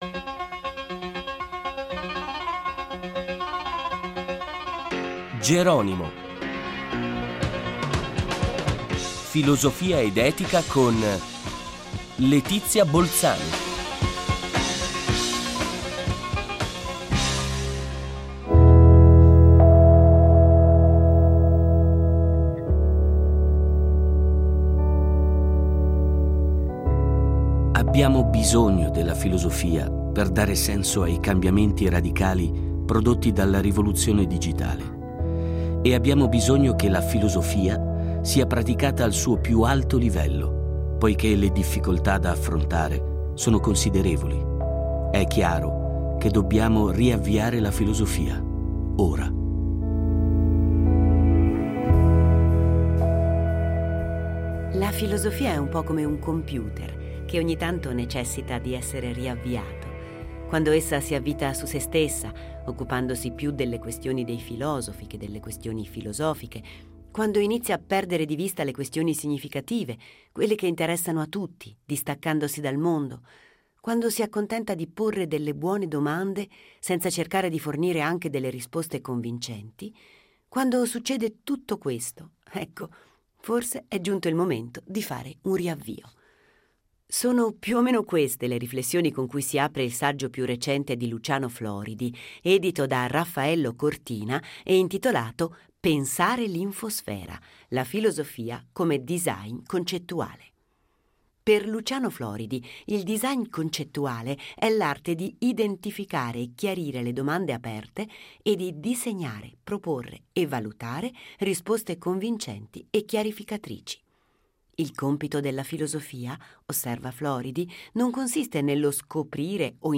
Intervista a Luciano Floridi